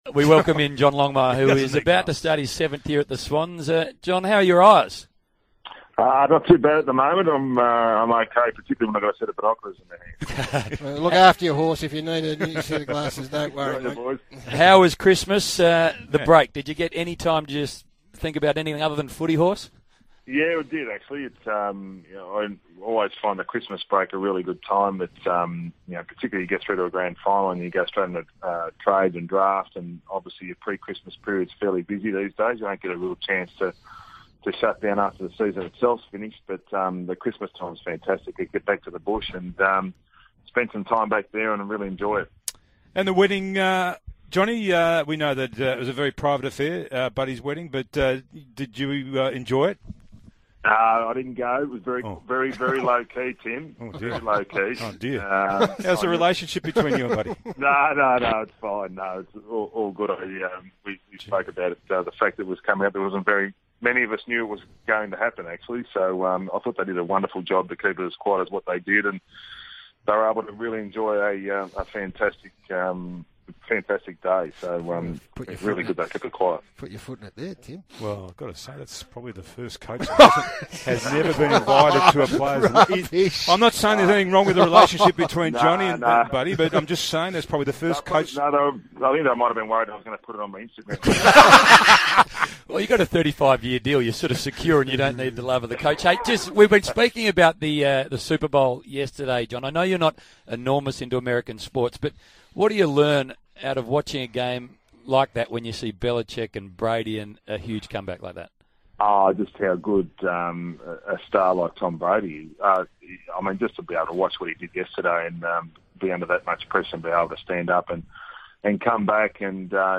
Coach John Longmire speaks to Garry Lyon, Tim Watson and Hamish McLachlan on SEN Radio.